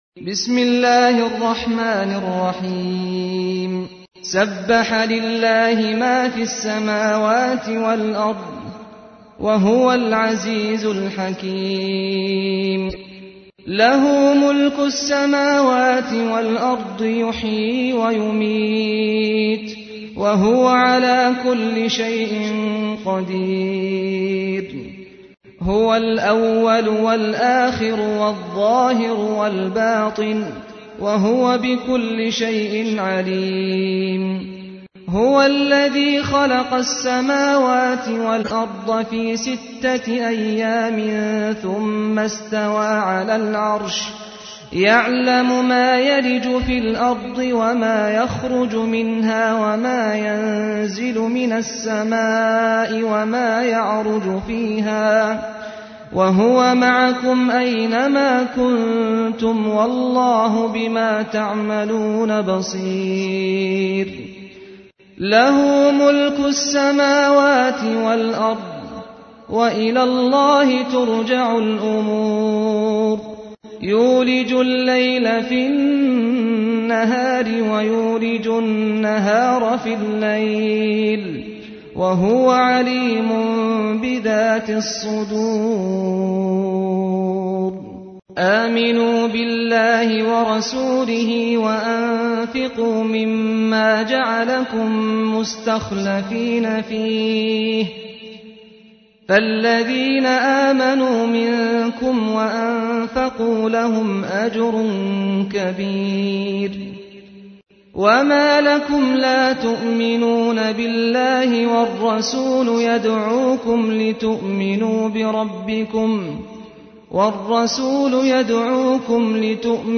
تحميل : 57. سورة الحديد / القارئ سعد الغامدي / القرآن الكريم / موقع يا حسين